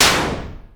ihob/Assets/Extensions/RetroGamesSoundFX/Shoot/Shoot24.wav at master
Shoot24.wav